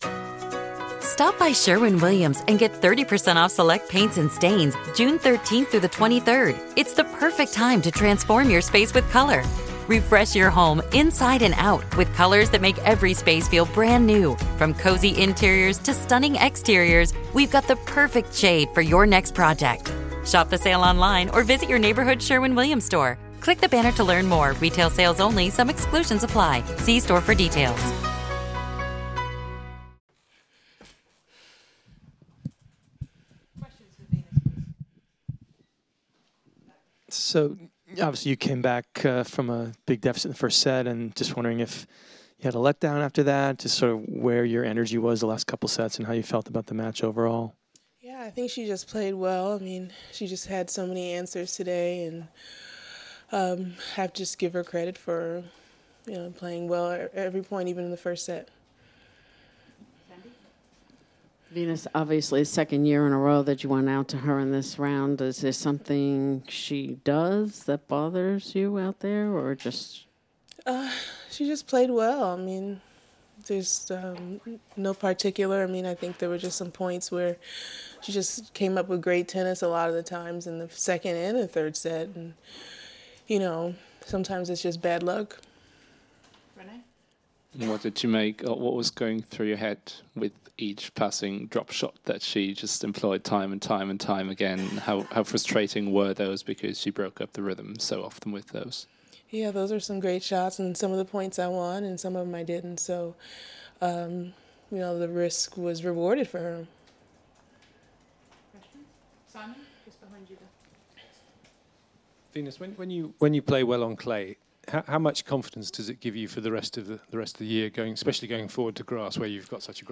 Here's Venus after her 4th round loss to Timea Bacsinszky at Roland-Garros